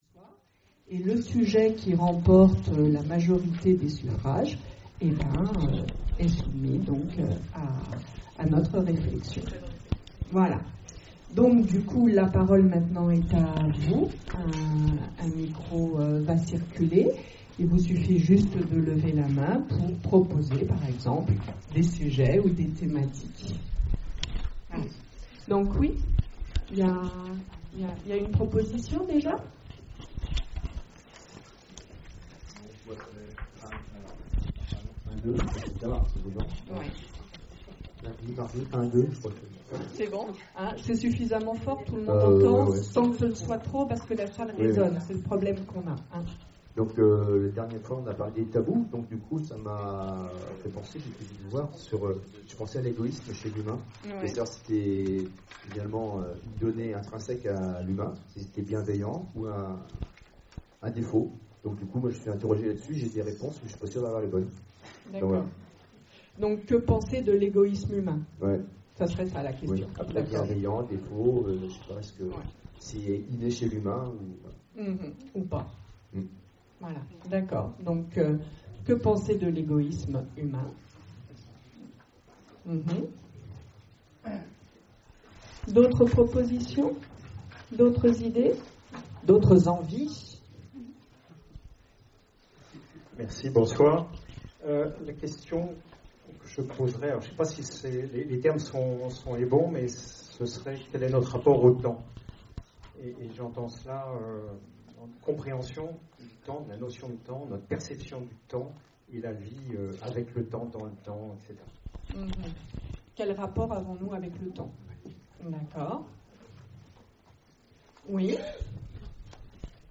Conférences et cafés-philo, Orléans
CAFÉ-PHILO PHILOMANIA L’identité est-elle un enfermement ?